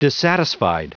Prononciation du mot dissatisfied en anglais (fichier audio)
Prononciation du mot : dissatisfied